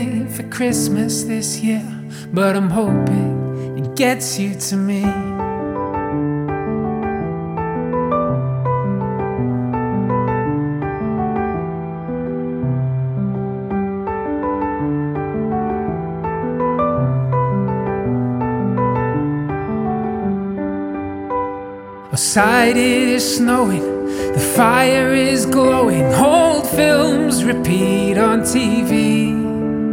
• Pop